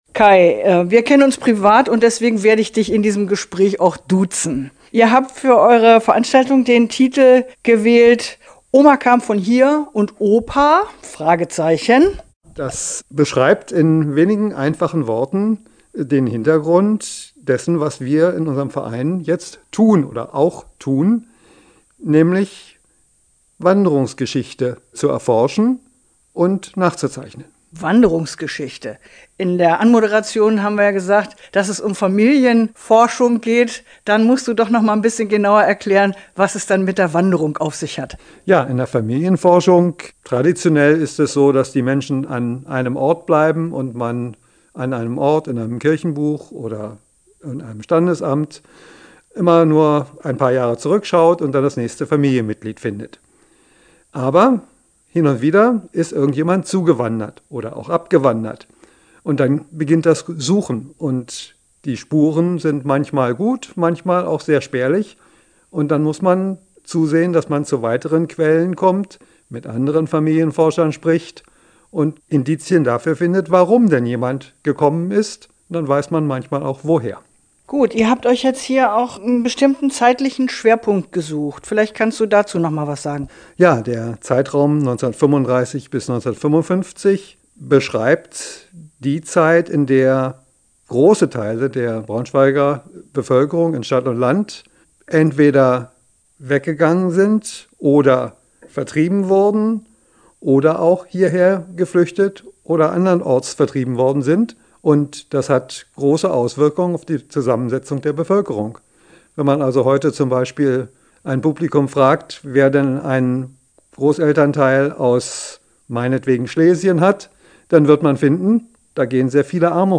Interview-Genealogie.mp3